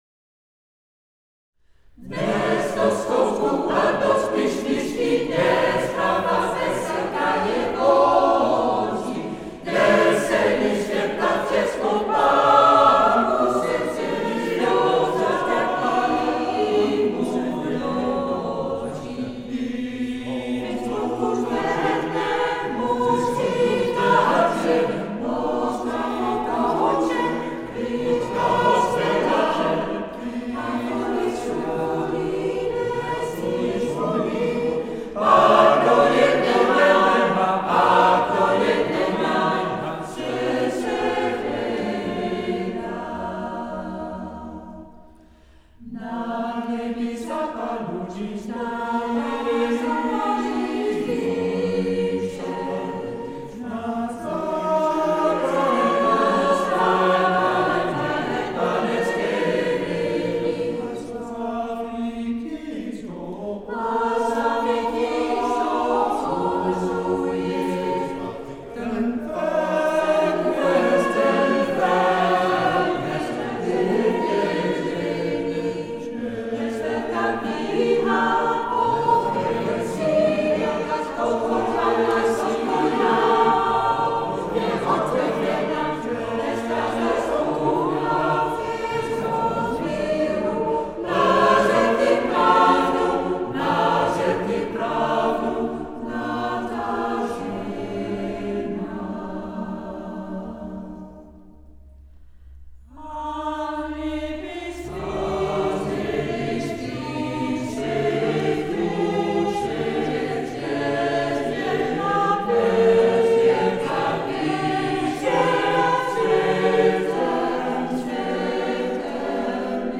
Hier staan alle takes als een soort archief bij elkaar.